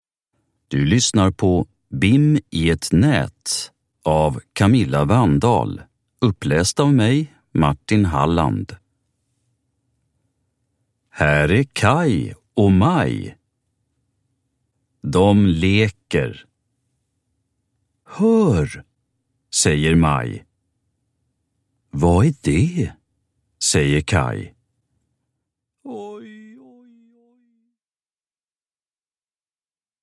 Bim i ett nät – Ljudbok